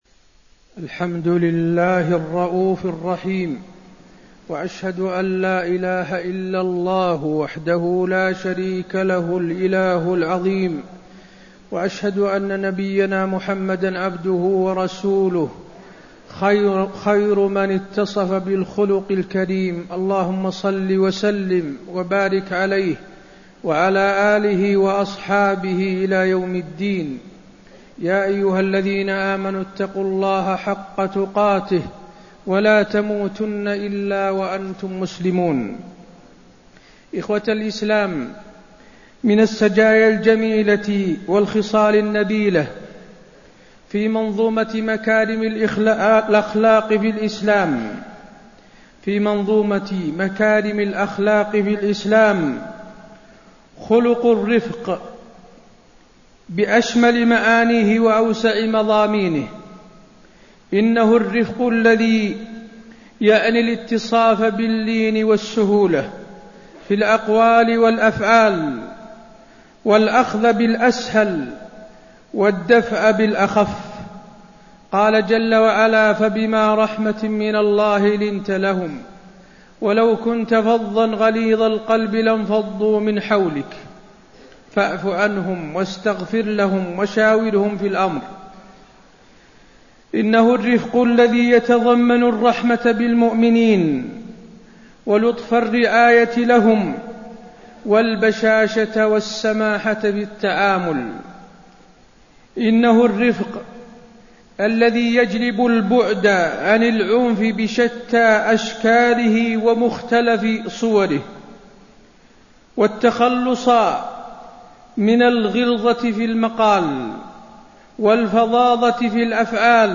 تاريخ النشر ٩ شعبان ١٤٣٣ هـ المكان: المسجد النبوي الشيخ: فضيلة الشيخ د. حسين بن عبدالعزيز آل الشيخ فضيلة الشيخ د. حسين بن عبدالعزيز آل الشيخ الحث على الرفق